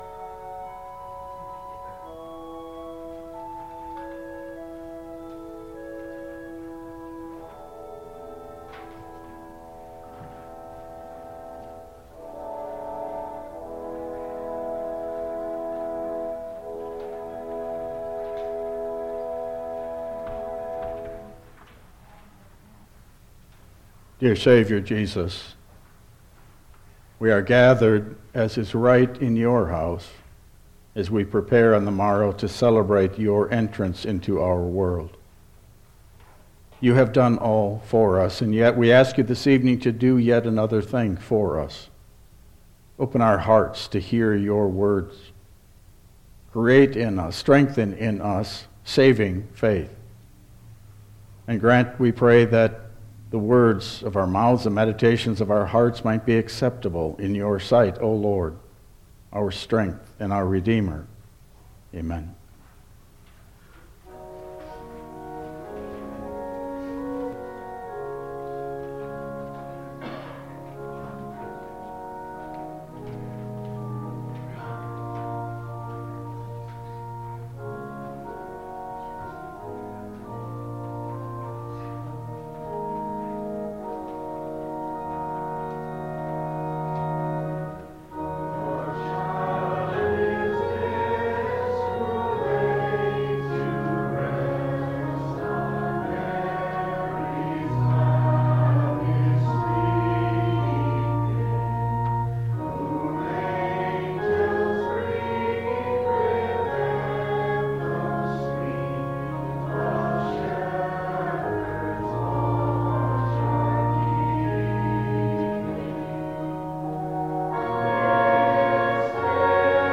Service Type: Christmas Eve